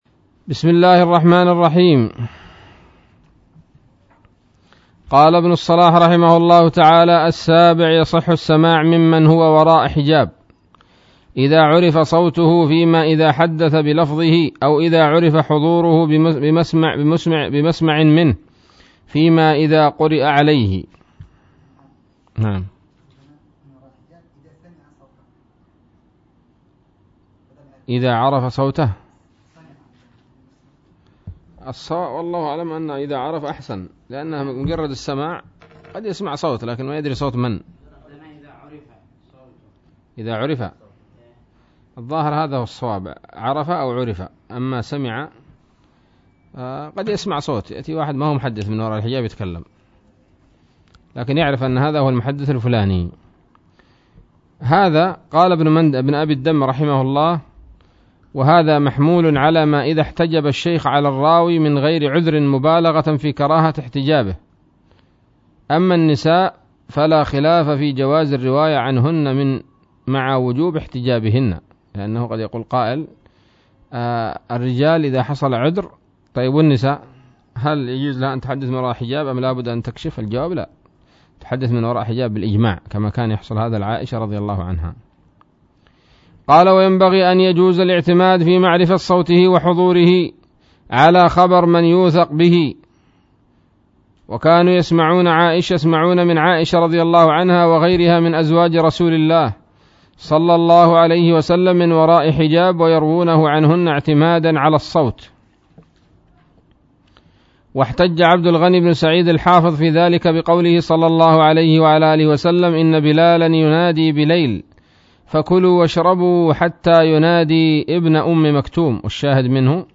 الدرس الخامس والستون من مقدمة ابن الصلاح رحمه الله تعالى